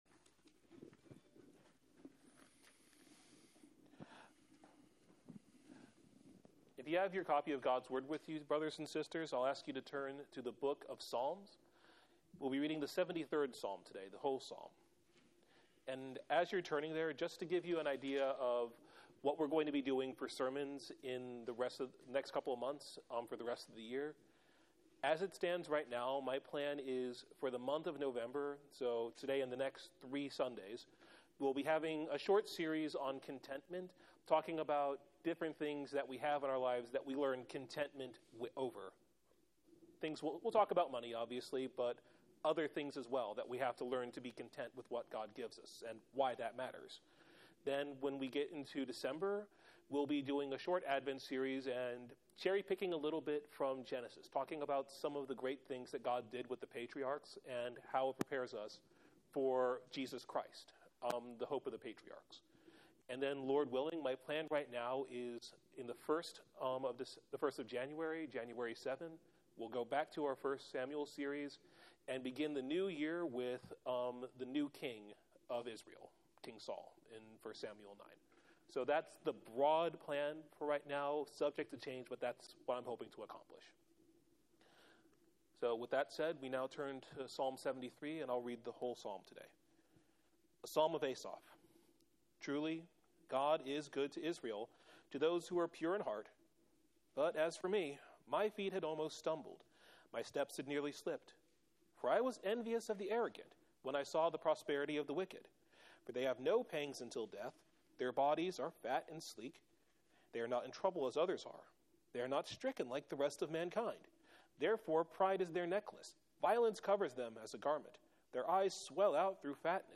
Sermon Text: Psalm 73:1-28 Theme: When the envious believer regains a proper understanding of God, he becomes content to possess God alone.